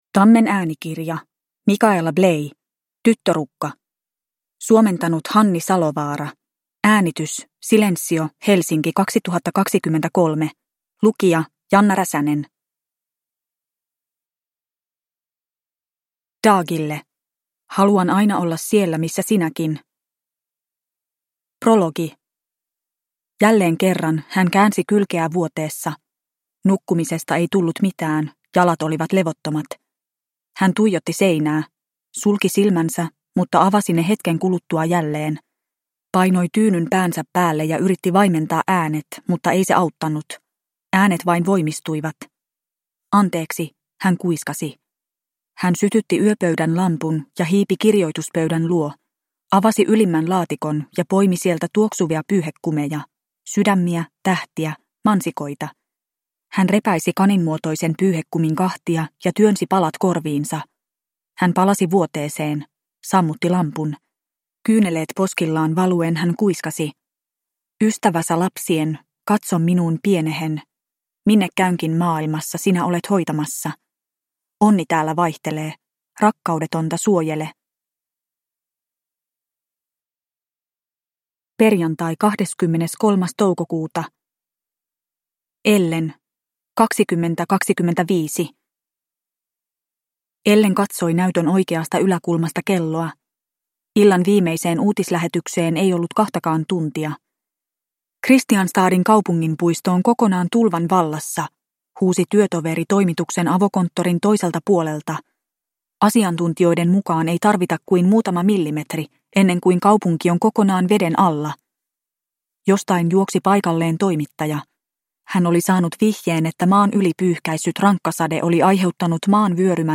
Tyttörukka – Ljudbok – Laddas ner